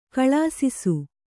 ♪ kaḷāsisu